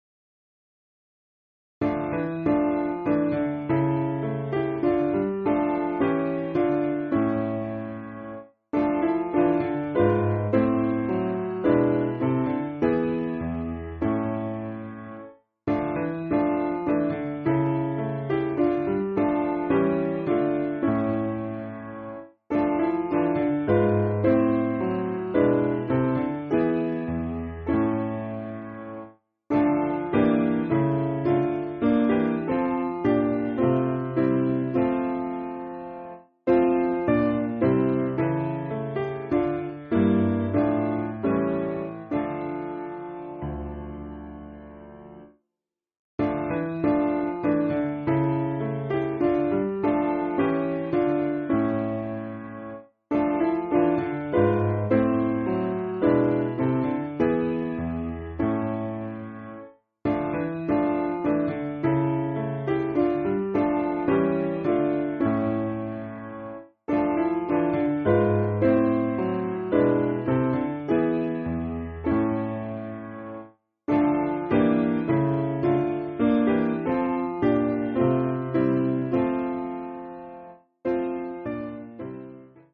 Simple Piano
(CM)   5/Dm